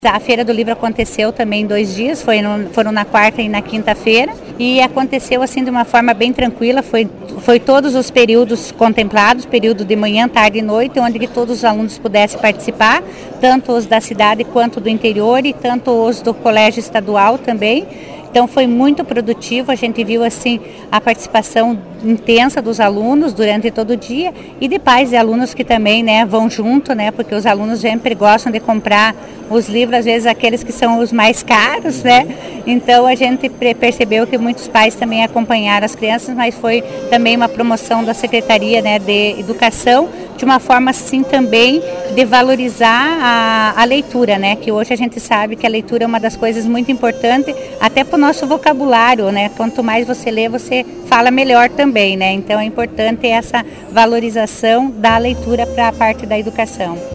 A prefeita Marisa de Fátima de Souza, comentou a importância da realização da Feira do Livre, e a participação dos alunos da Rede Municipal como Estadual.
PREFEITA-MARISA-DE-SOUZA-PARTE-05-FALA-DA-FEIRA-DO-LIVRO-QUE-ACONTECEU-EM-PORTO-VITÓRIA.mp3